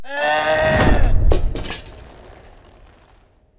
defeat_a.wav